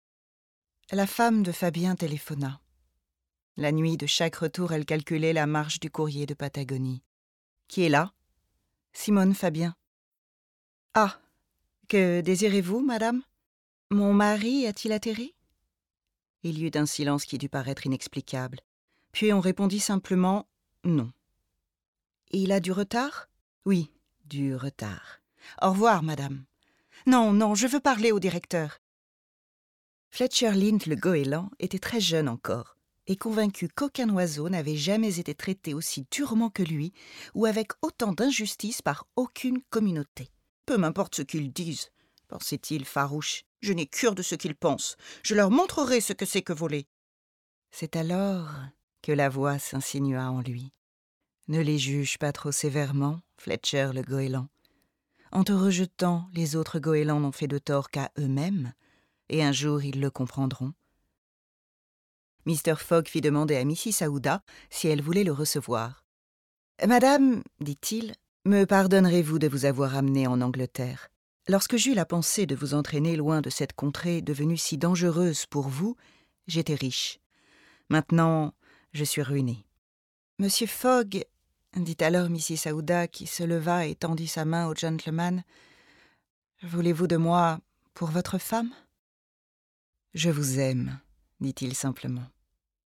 Bande démo publicité
30 - 55 ans - Mezzo-soprano